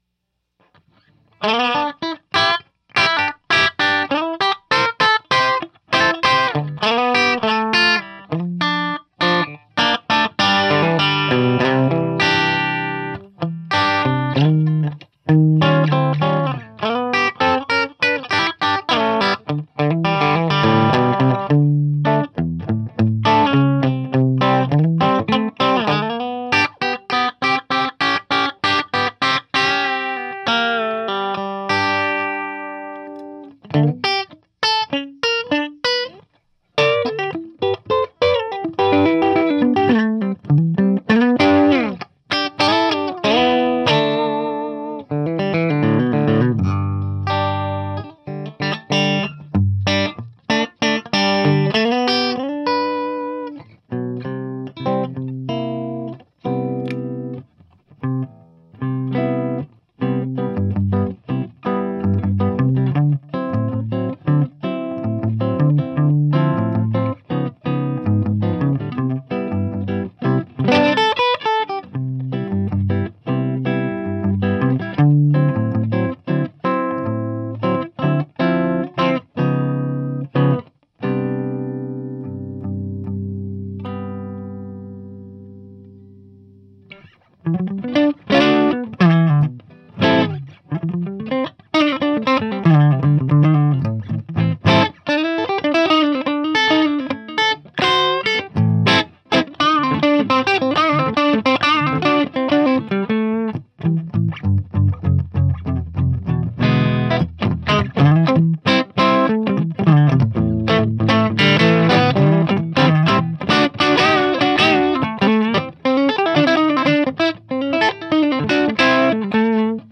The cab contains vintage G12-65's which are not the brightest and chimiest speaker in the Celestion stable and have smoother top end than newer production Celestions. The cab was mic'ed with a single SM57 aimed at the upper right hand speaker.
I did one clean pass and one overdriven pass for each guitar.